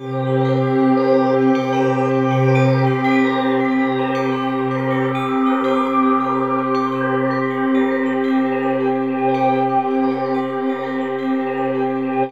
36aj02pad1cM.wav